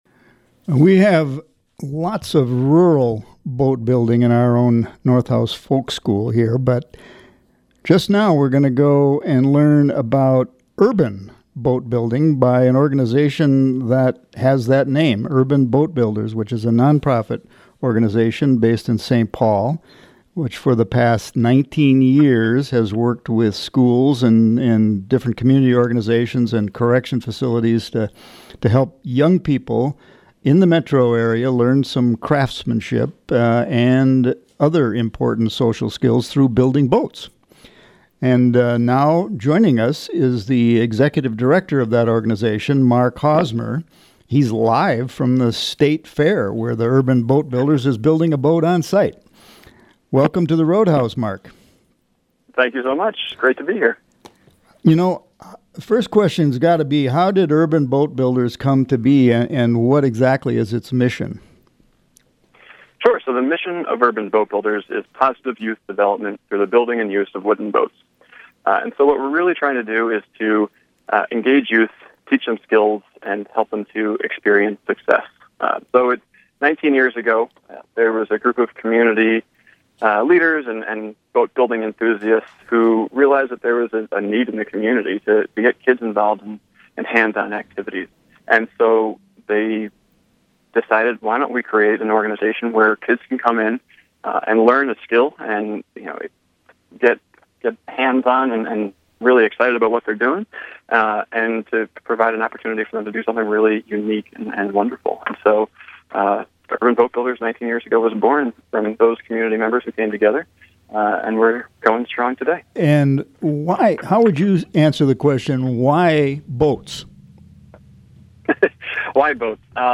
from the State Fair, where they were building a boat on site